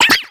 Cri de Sepiatop dans Pokémon X et Y.